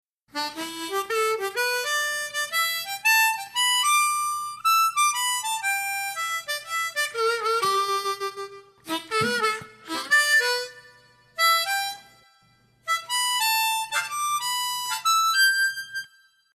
La base musical que encontrarás en la clase es en tonos MI menore y tocaremos una armónica en C tocando en quinta posición.
TÉCNICA 2: REPETICIÓN TRASLADA. A diferencia de la técnica anterior, no se repite la misma línea melódica, sino que se traslada a otra zona del instrumento: un ejemplo muy frecuente del uso de la repetición traslada es cuando se ejecuta una octava más arriba o más abajo.